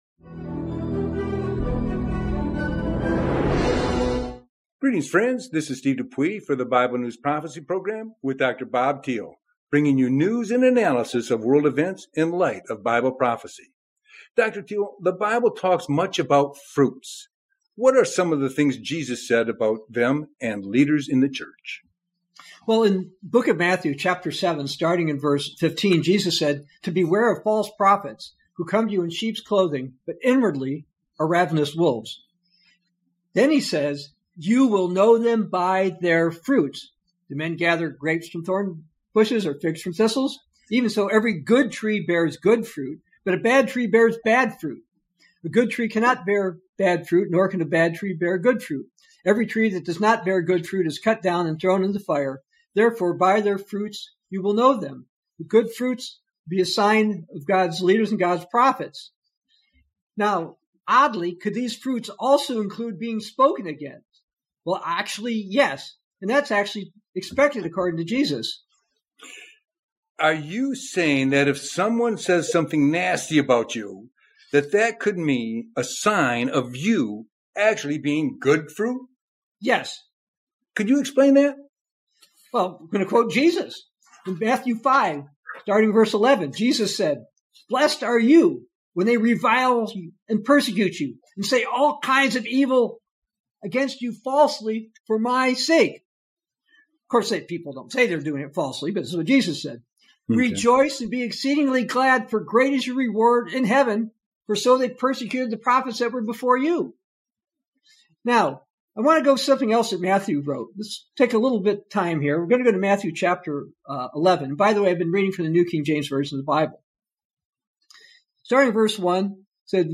Bible News Prophecy Show